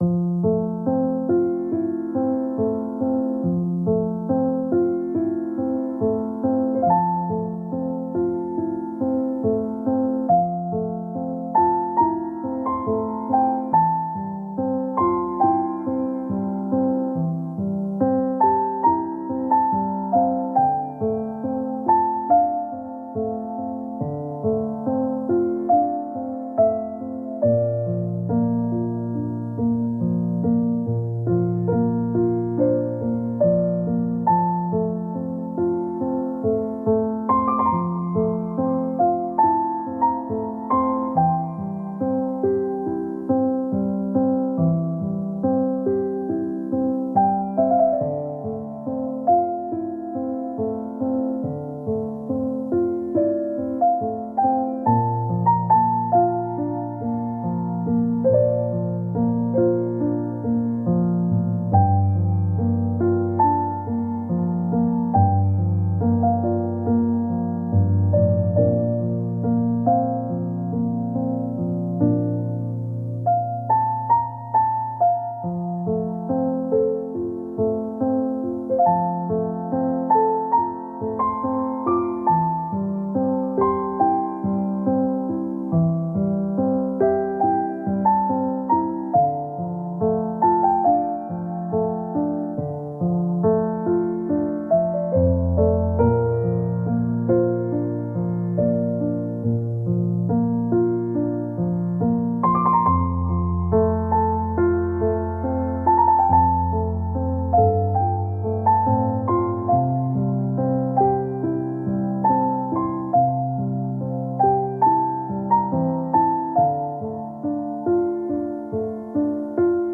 ENTSPANNENDE GEDANKEN-TRÄGER: Meeresküste-Wellen-Gedankenträger tragen Wellen fort
Naturgeräusche